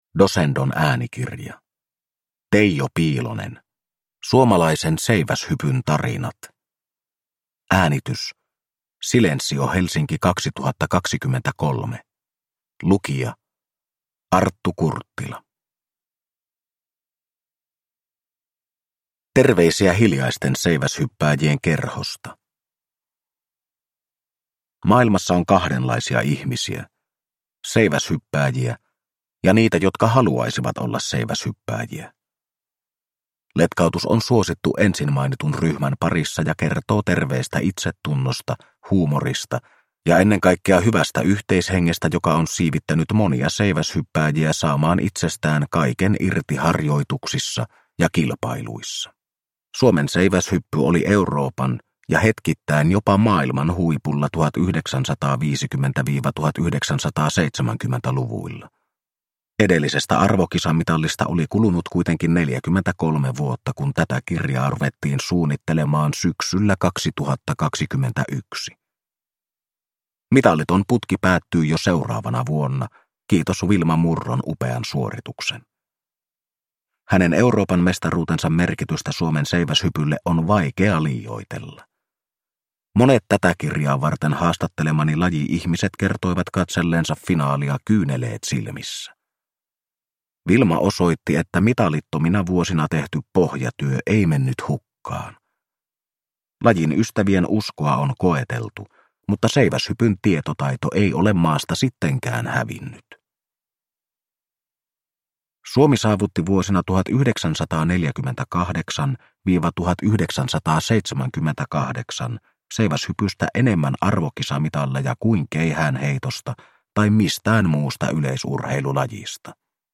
Suomalaisen seiväshypyn tarinat – Ljudbok – Laddas ner